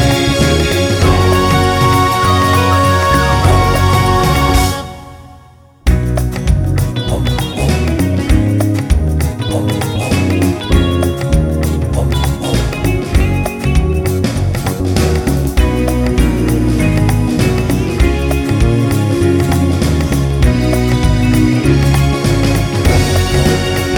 no Backing Vocals Soundtracks 2:49 Buy £1.50